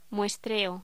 Locución: Muestreo
voz